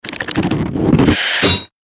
Gemafreie Sounds: Uhren, Glocken, Klingeln
mf_SE-99-clattering_noise_fx.mp3